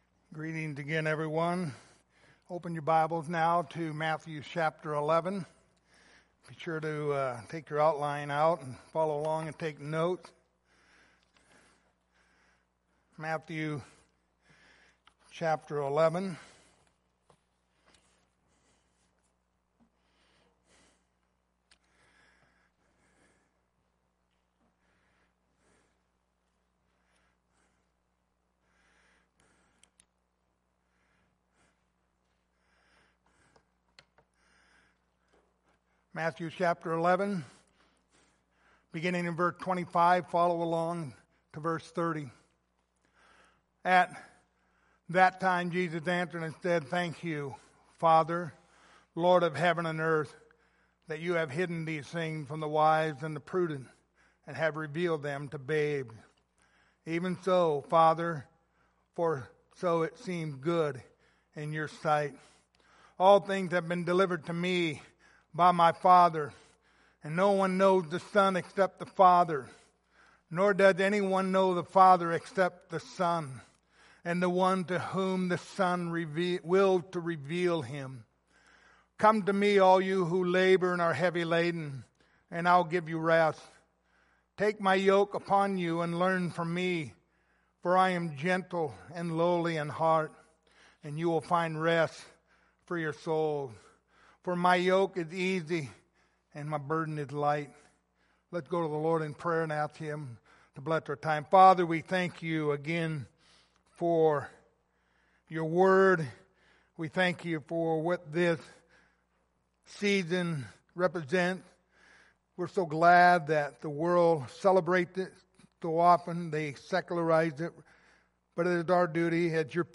Christmas Passage: Matthew 11:28 Service Type: Sunday Morning Topics